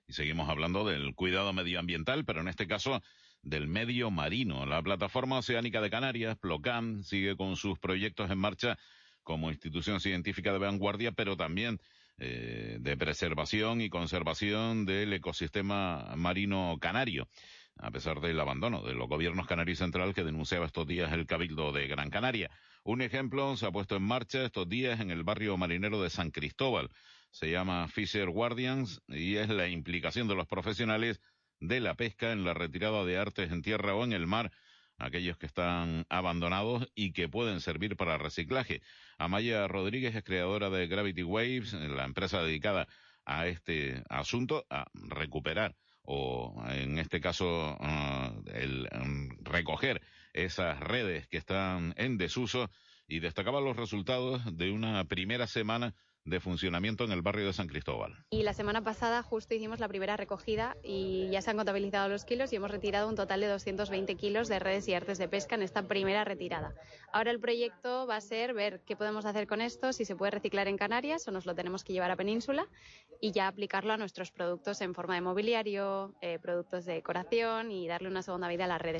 Radio interviews: